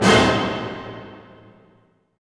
HIT ORCHD0CL.wav